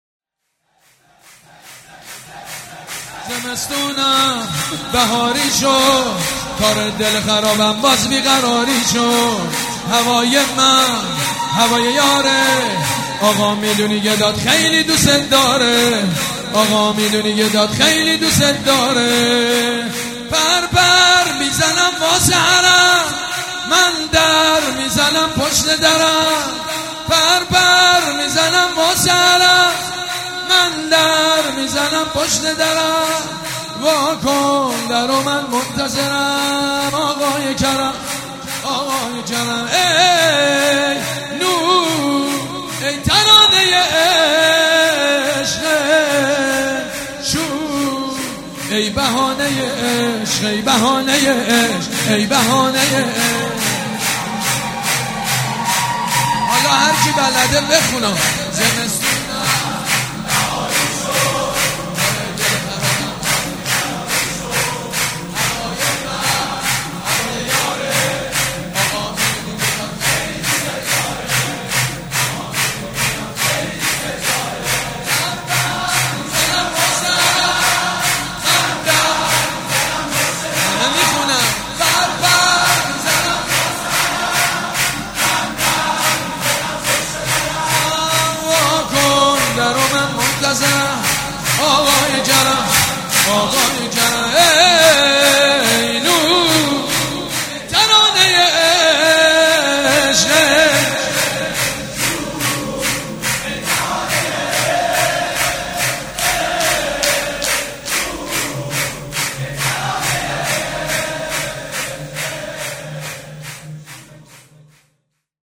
شور
مداح
حاج سید مجید بنی فاطمه
جشن نیمه شعبان